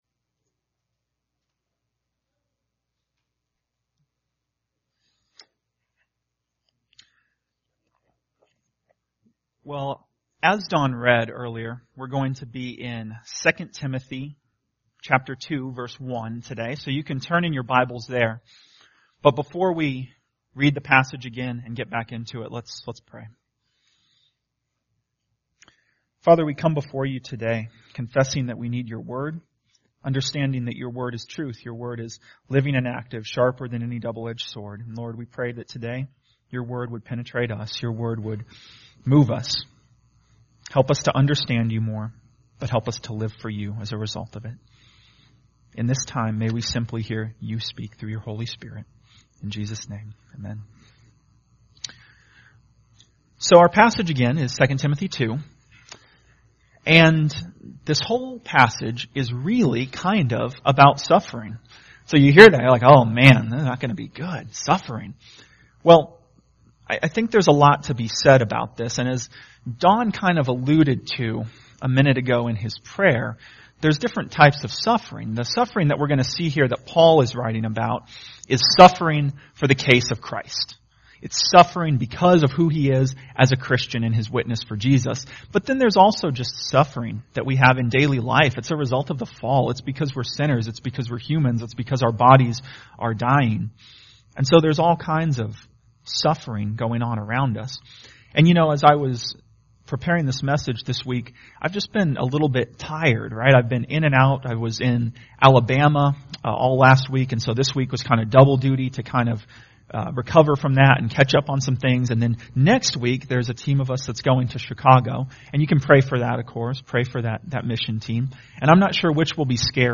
Sunday Worship
Tagged with Sunday Sermons